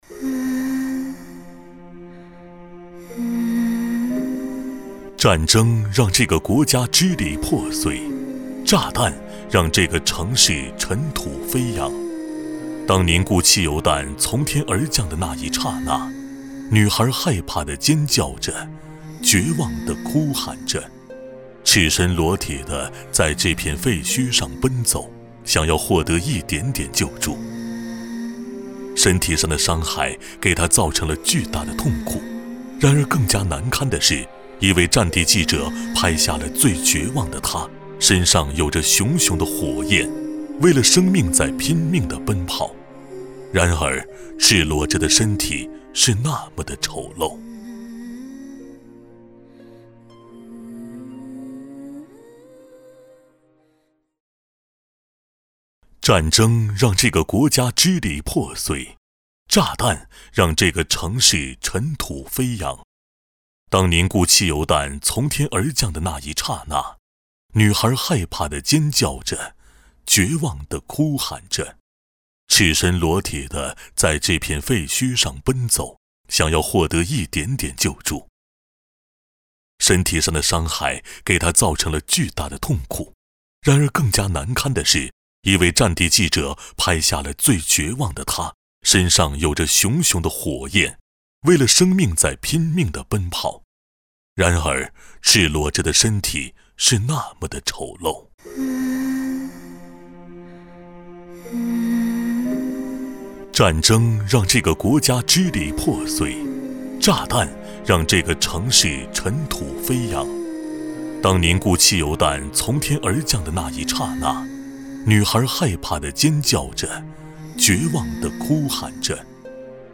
职业配音员全职配音员浑厚大气
• 男S310 国语 男声 旁白-受伤的鸟儿也能飞翔-感性 低沉|调性走心|感人煽情|素人